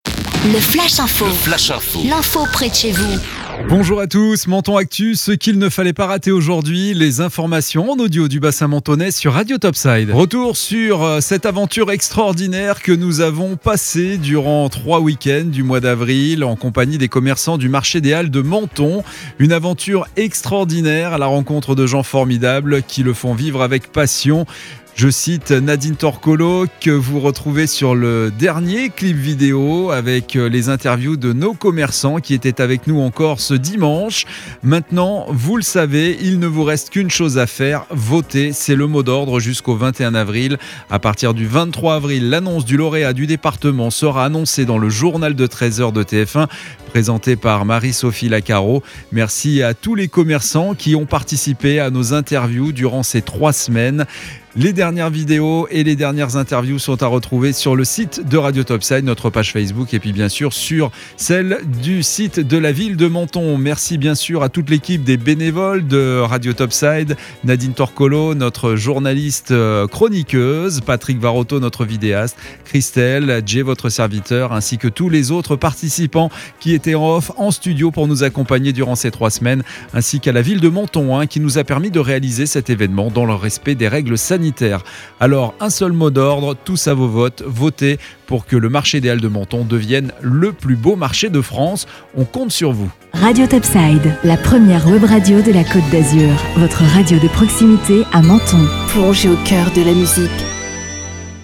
Menton Actu - Le flash info du lundi 19 avril 2021